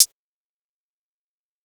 HiHat (32).wav